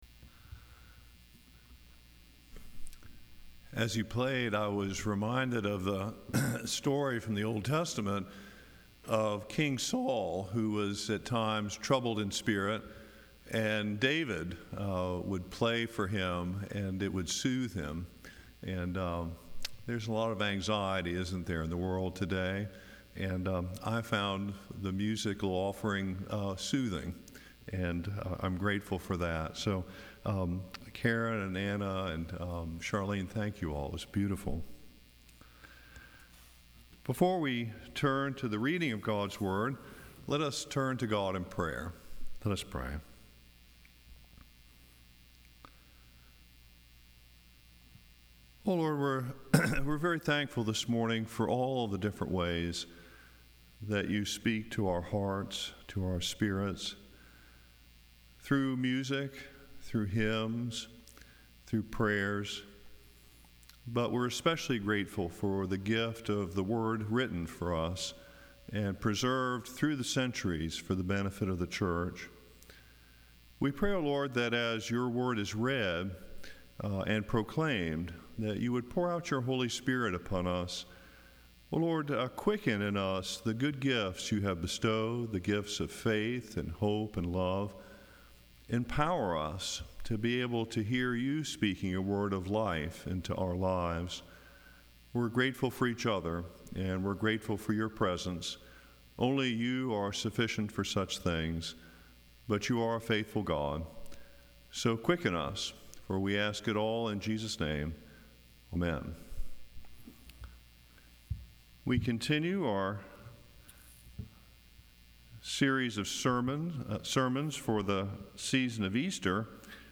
Acts 3:1-10 Service Type: Sunday Morning Sermon Text